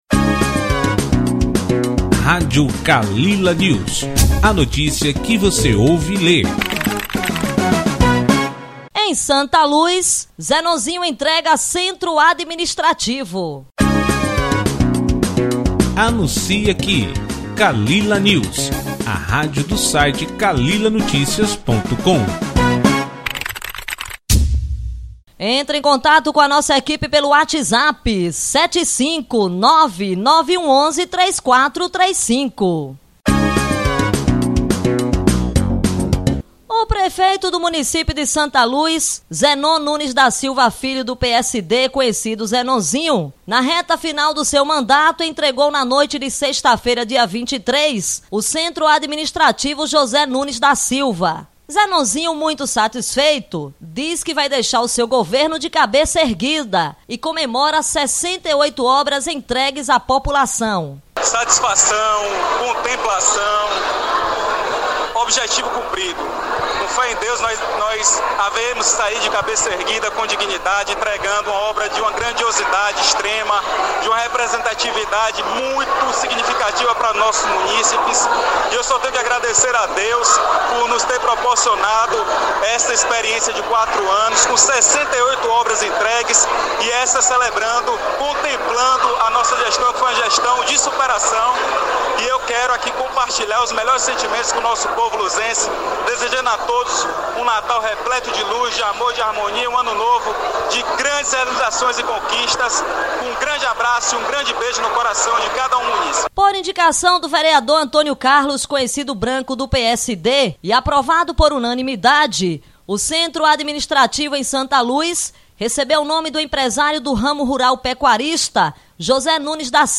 Os discursos aconteceram sob uma leve garoa, mas a satisfação pela obra não intimidou as autoridades e parte do público que acompanhou atentamente os pronunciamentos.
S-LUZ-INAUGURACAO.mp3